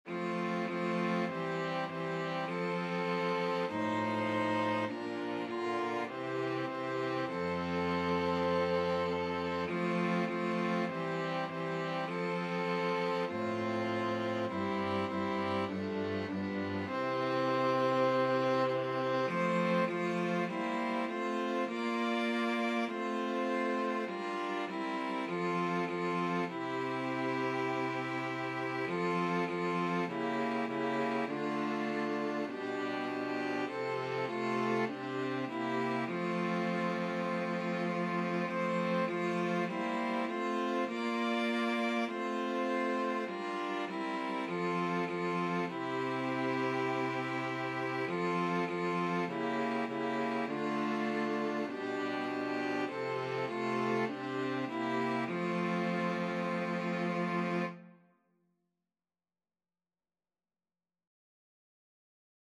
Free Sheet music for String Quartet
Violin 1Violin 2ViolaCello
F major (Sounding Pitch) (View more F major Music for String Quartet )
4/4 (View more 4/4 Music)
String Quartet  (View more Easy String Quartet Music)
Classical (View more Classical String Quartet Music)